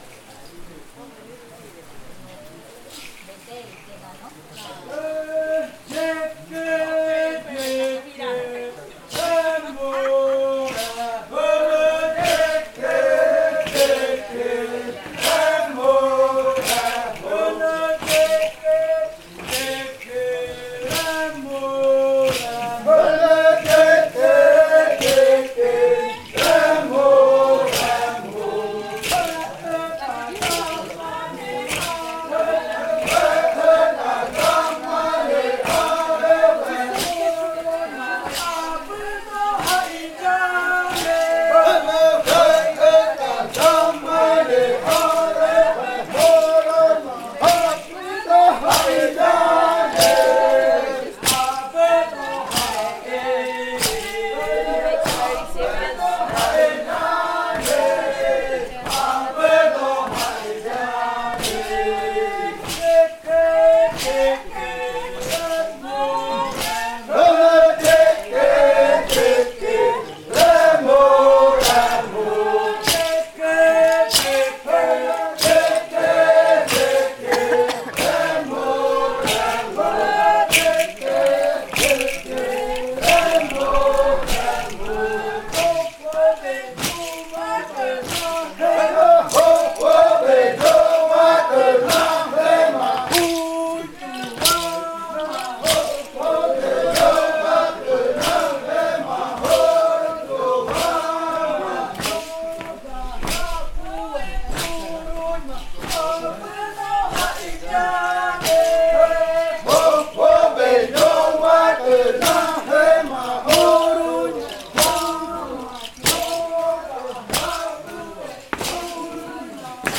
Canto de amanecida de la variante muruikɨ
Leticia, Amazonas
con el grupo de cantores bailando en Nokaido.
with the group of singers dancing in Nokaido.